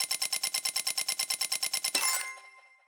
Prize Wheel Spin 1 (short).wav